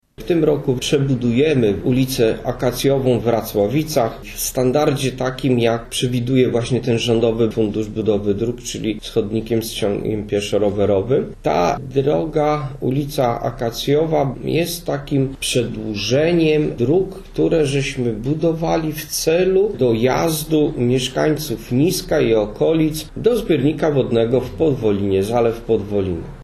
Burmistrz Niska Waldemar Ślusarczyk przyznał , że ta inwestycja jeszcze bardziej poprawi skomunikowanie różnych części gminy ze zbiornikiem Podwolina: